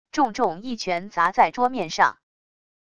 重重一拳砸在桌面上wav音频